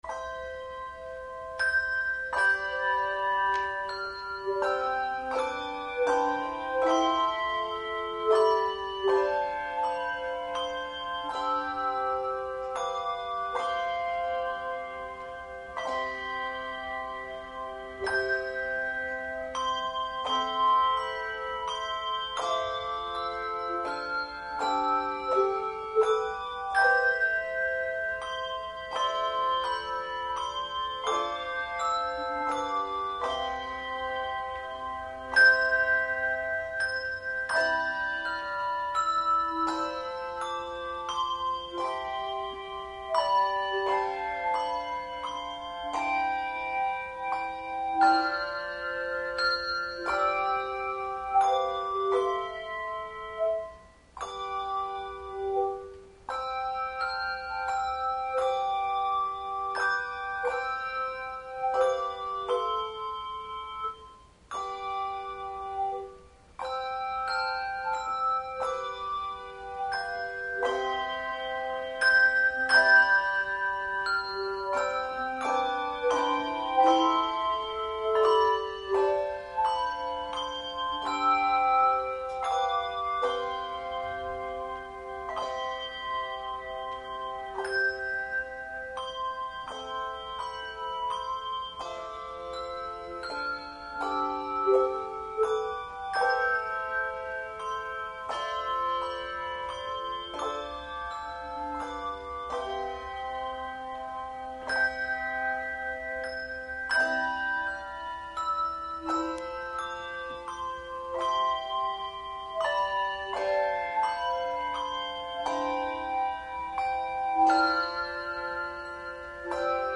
Handbell Quartet
Genre Sacred
No. Octaves 2 Octaves